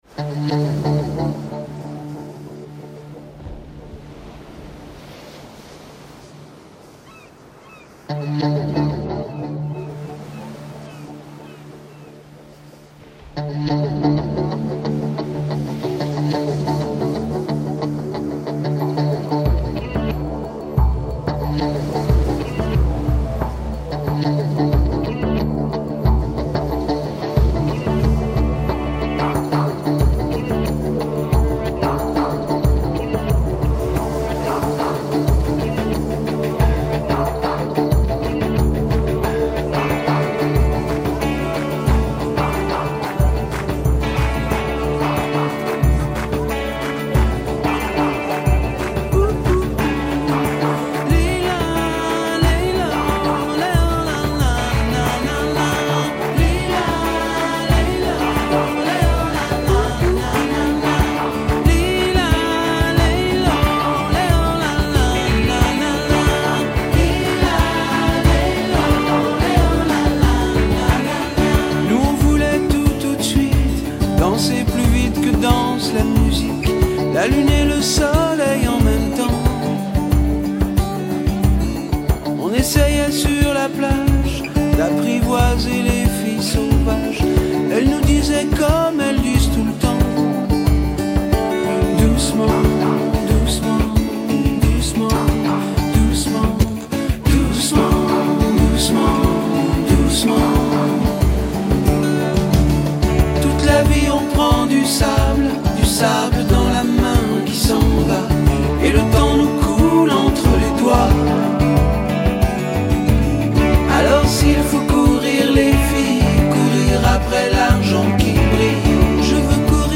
tonalité RÉ